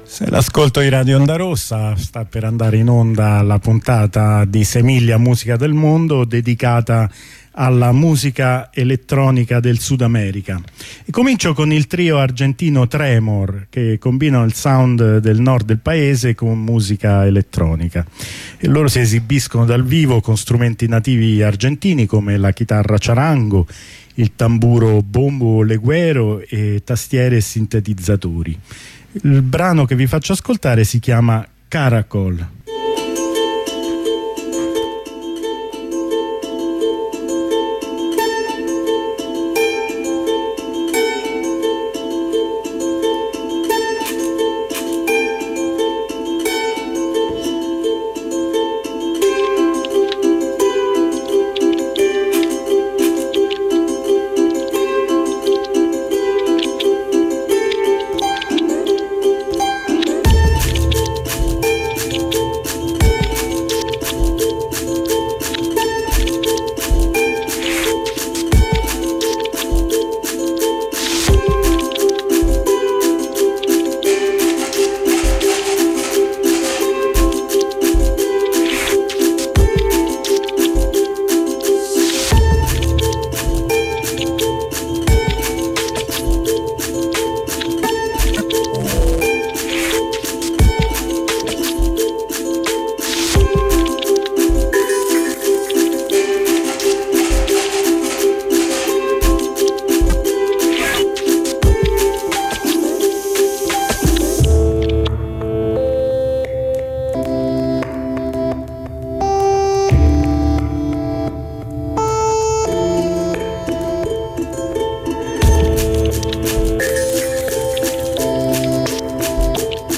elettronica sudamerica ok.ogg